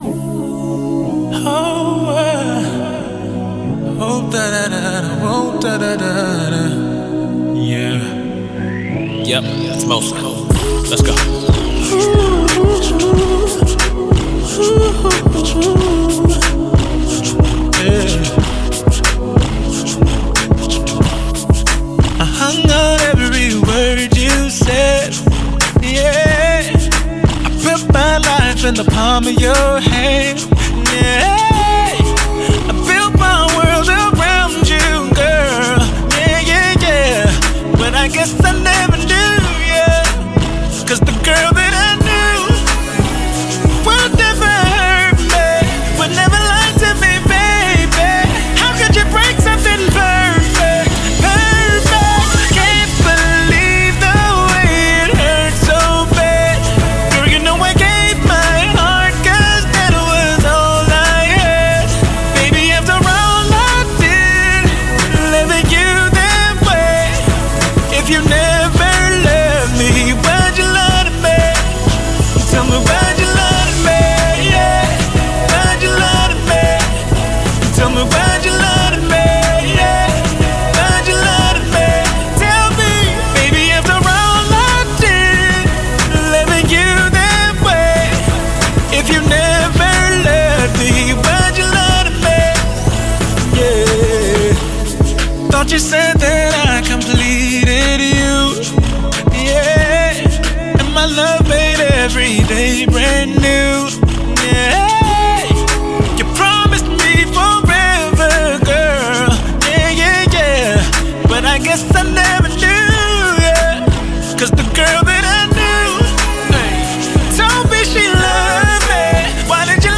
classic Pop/R&B